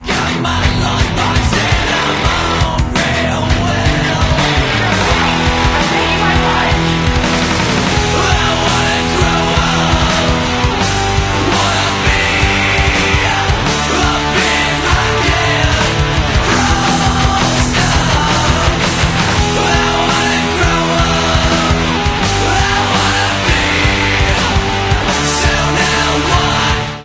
Reduced quality: Yes
It is of a lower quality than the original recording.